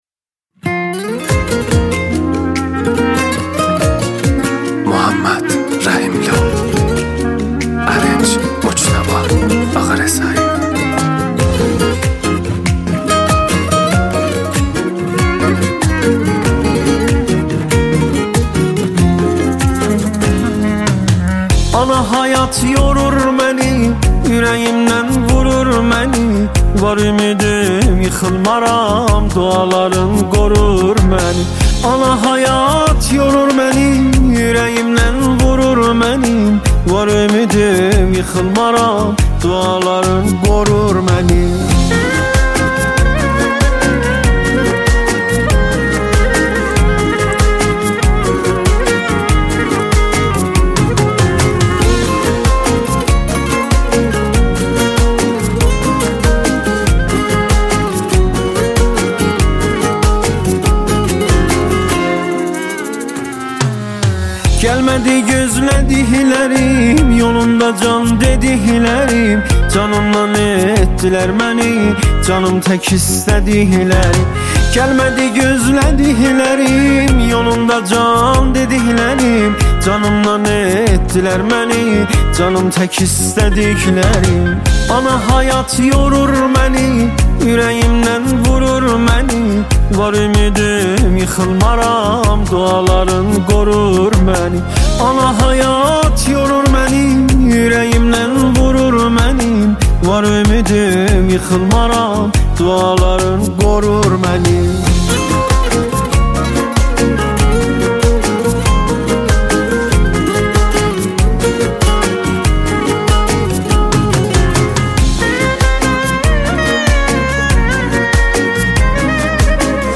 آهنگ ترکی